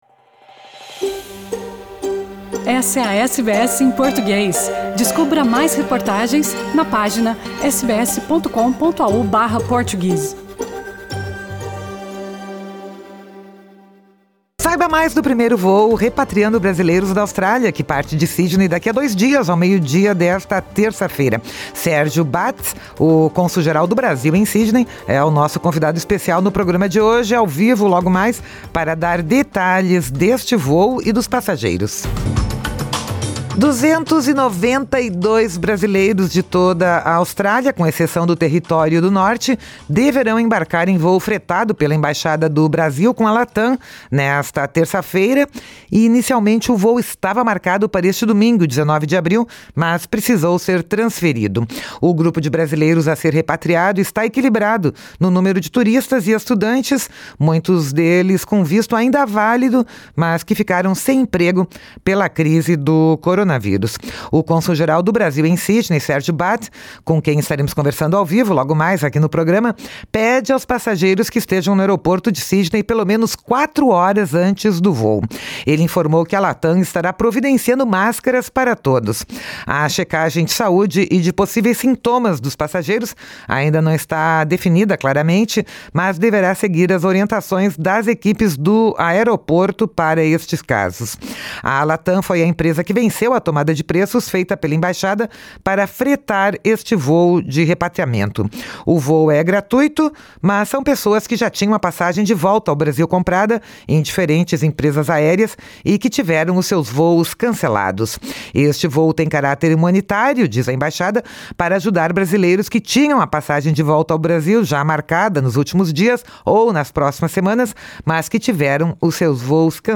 Nesta entrevista à SBS em Português, o Cônsul-Geral Sérgio Bath explica como o voo vai proceder e conta como foi o processo de organização desta operação.